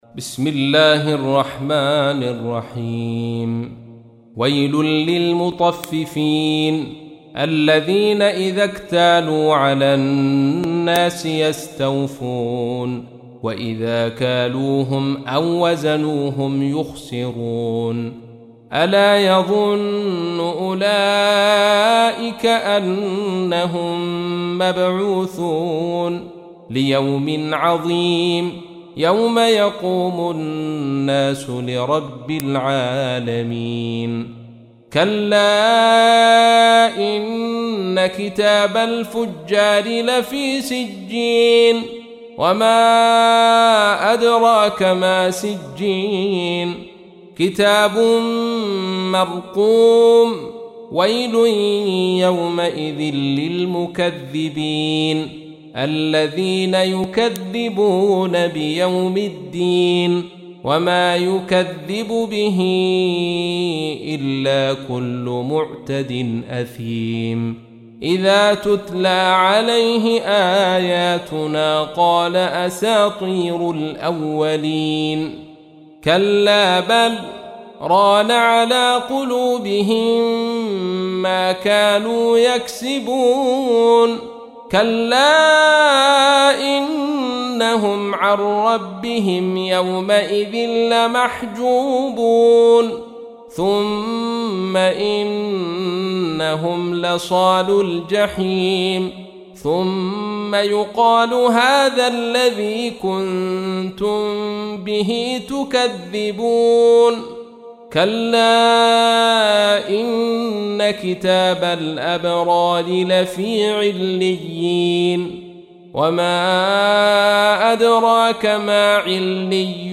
تحميل : 83. سورة المطففين / القارئ عبد الرشيد صوفي / القرآن الكريم / موقع يا حسين